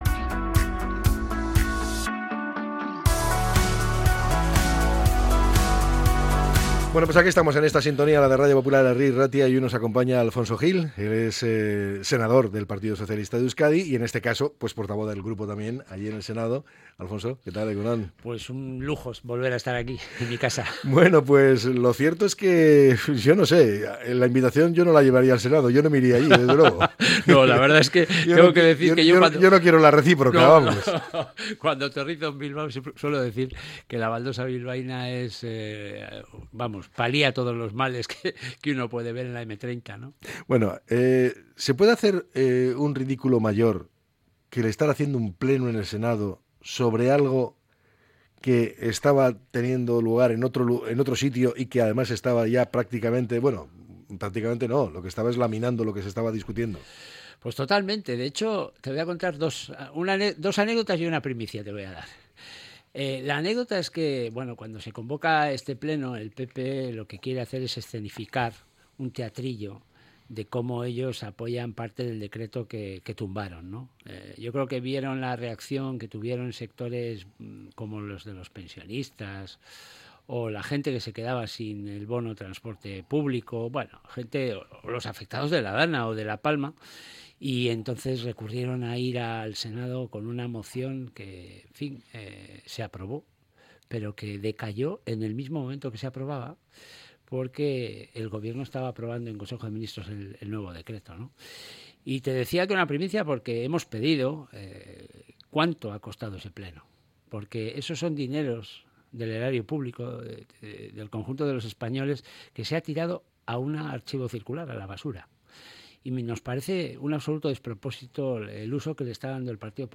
El senador del Partido Socialista de Euskadi, Alfonso Gil, ha visitado los estudios de Radio Popular - Herri Irratia.
Entrevista con el senador del PSOE para analizar la reciente sesión plenaria y la situación política actual